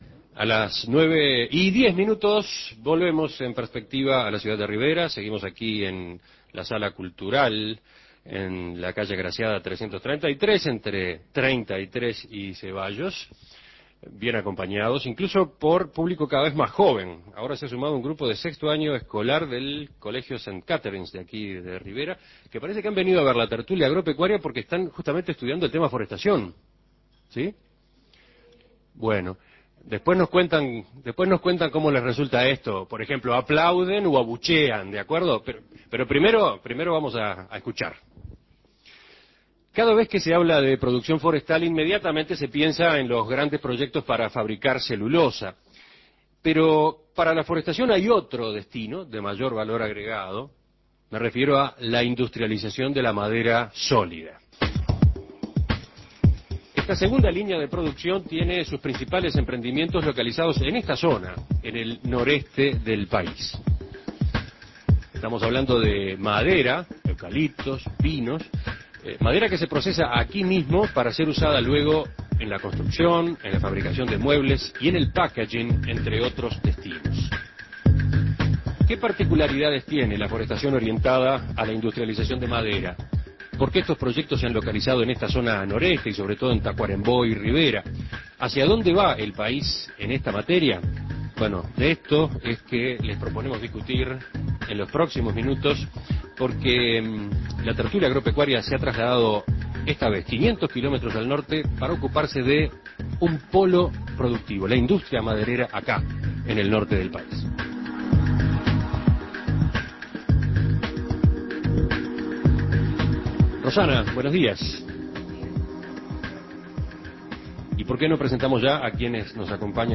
Escuche la Tertulia Agropecuaria